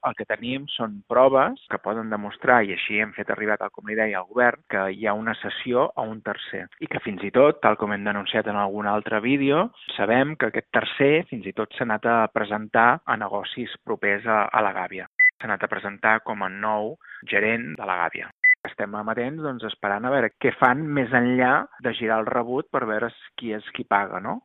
El portaveu republicà i cap de l’oposició, Xavier Ponsdomènech, afirma que en tenen evidències i que les han posat en coneixement del govern, que no sap si ha ordenat alguna acció per comprovar-ho. Són declaracions a Ràdio Calella TV.